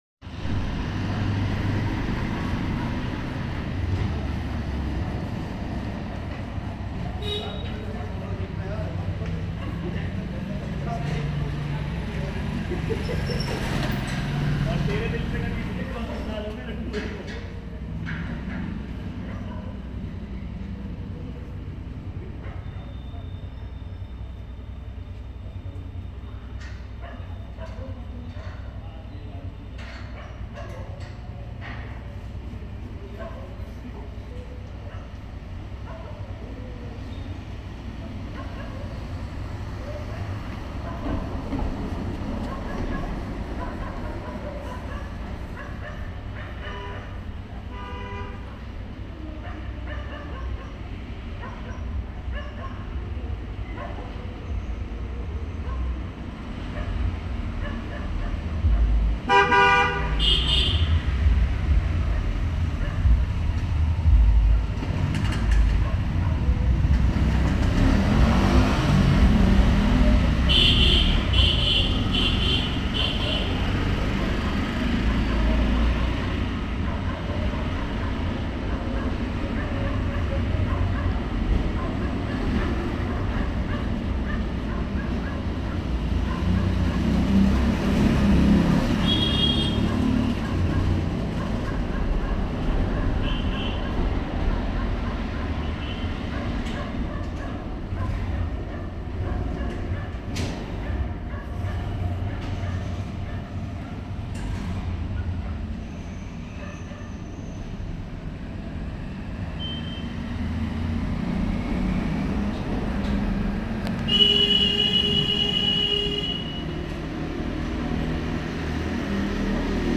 The rhythm of routine: sounds from my street
This recording captures the vibrant and dynamic sounds of a neighborhood in motion. As we listen, we hear the distinct bark of dogs, a common presence in many communities, signaling either the arrival of their owners or responding to the bustling street life. The intermittent honking of cars weaves through the audio, painting a picture of a busy roadway, perhaps with traffic navigating through the hustle and bustle of daily life. Amidst these sounds, the murmur of people talking can be heard, their conversations blending into the ambient noise of the street. These voices, though indistinct, reflect the daily interactions and exchanges that are integral to community life.